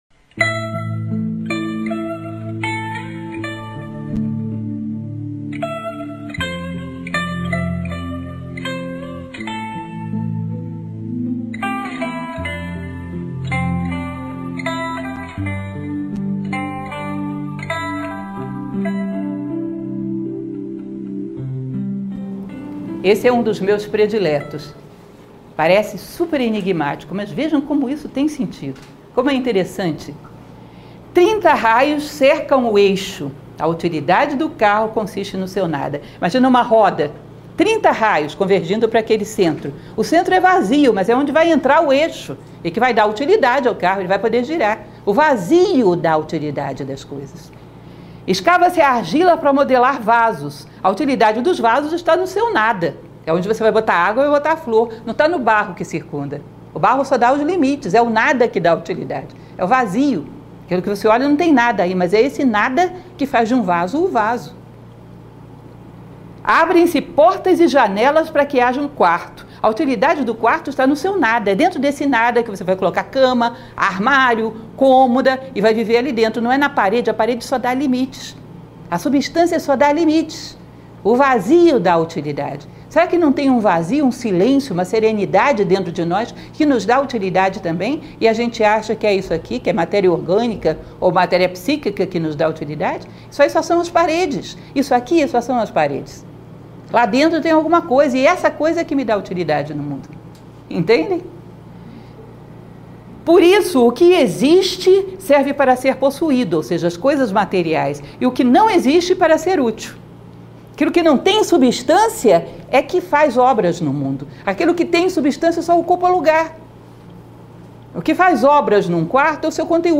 Palestras Filosóficas